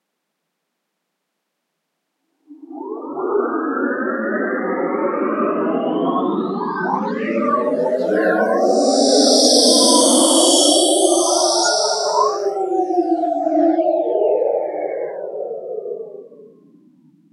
В том, что полученный частотный паттерн не имеет шума, чистый сигнал, разложенный в ряд Фурье, точно также, как и работает наше зрительное восприятие.
Полученный mp3-файл можно послушать, звучит чисто, это не гаряевские шумы -